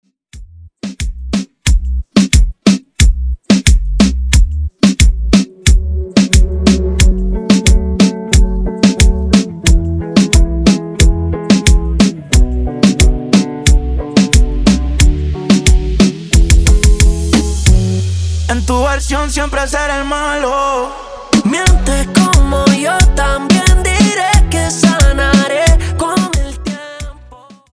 Extended Dirty Coro